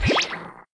Trade Inventory Zoom Sound Effect
trade-inventory-zoom.mp3